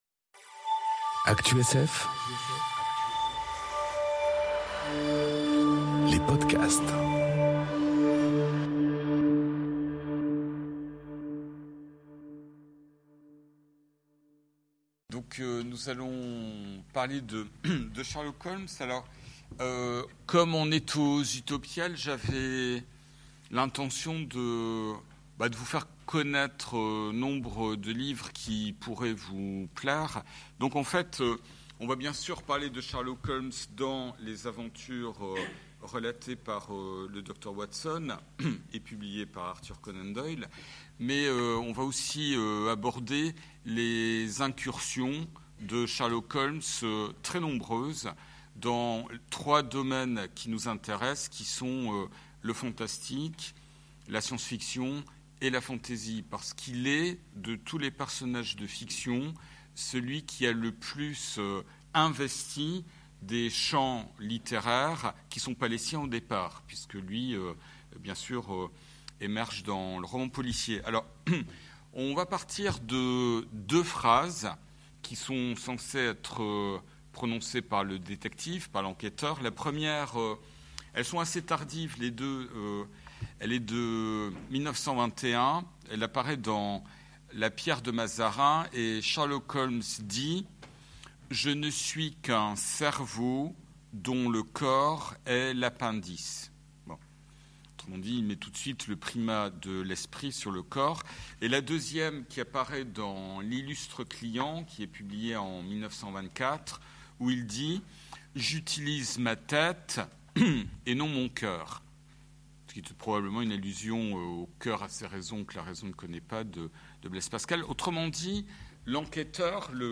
Conférence La raison désincarnée : Sherlock Holmes enregistrée aux Utopiales 2018